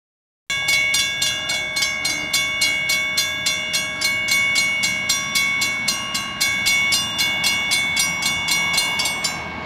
Crossing Bells My photos: UP6936 approaching Driving along side UP6936.
bells.wav